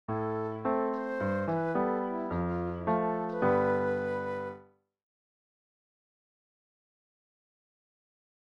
To give you an idea of the effect we’re talking about, here are some simple short melodic fragments, first given in a major key, and then in a minor key equivalent:
Am  G  F  G (
The minor mode gives the music a darker mood, with potential for a bit more edge. These are just midi files that I’ve posted here, so you’ll have to use your imagination and your own instrumentation to bring them to life.